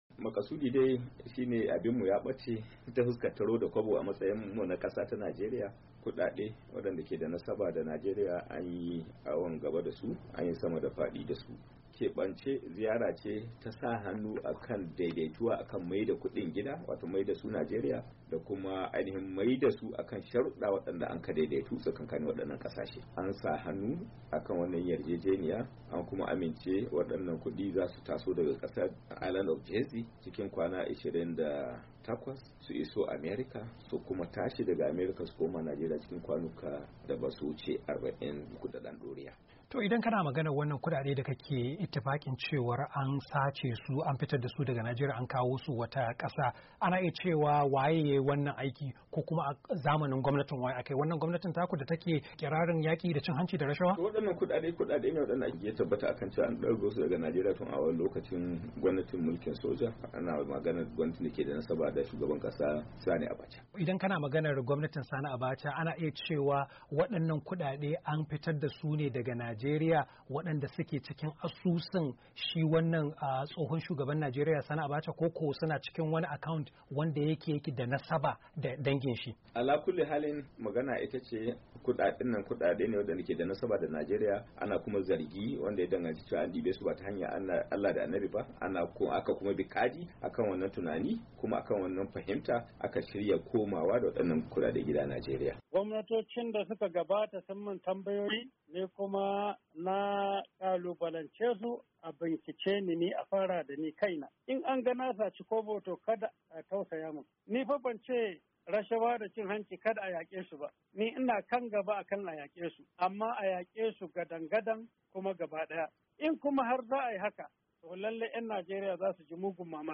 Hira Da Ministan Shari'a Abubakar Malami Da Kuma Hamza Al-Mustapha - 2'19"